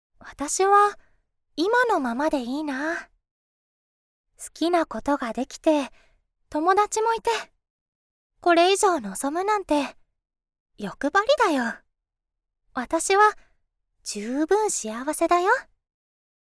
Kind girl